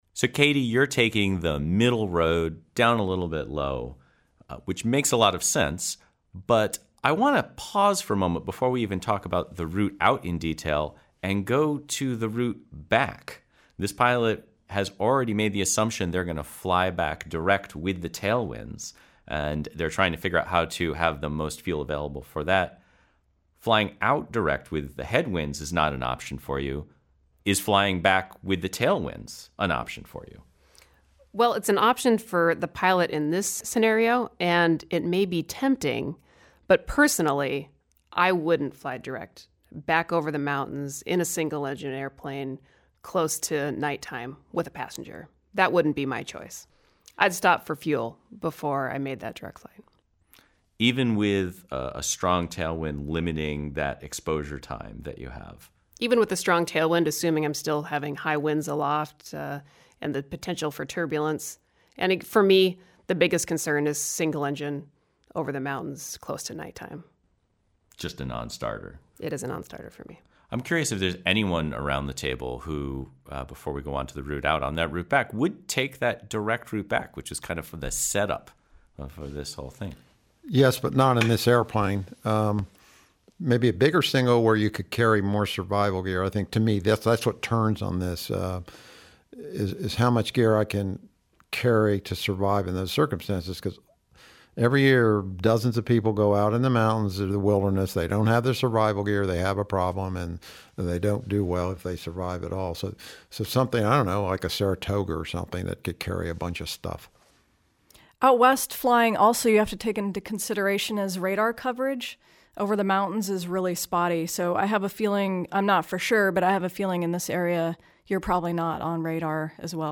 Which Way to Yellowstone_roundtable.mp3